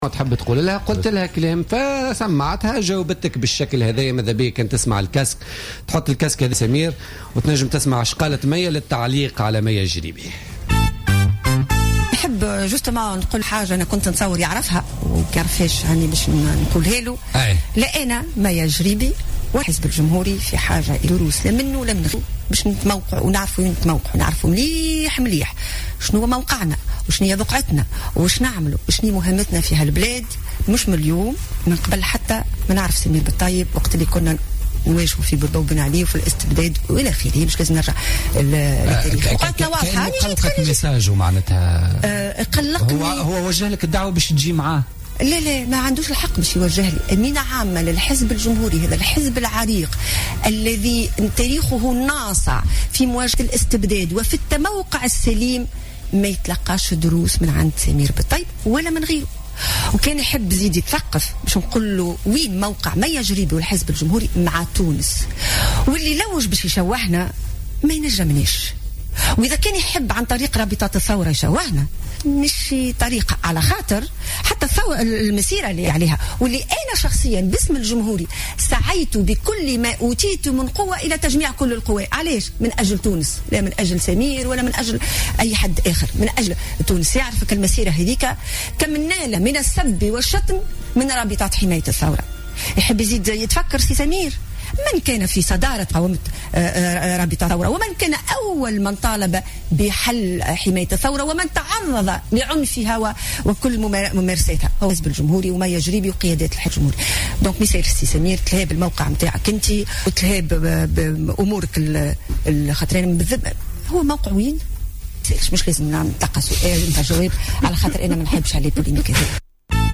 رفض الأمين العام لحزب المسار سمير بالطيب ضيف بوليتيكا اليوم الثلاثاء 22 ديسمبر 2015 الرد على تصريح مية الجريبي الذي طالبته فيه بعدم اعطائها دروس .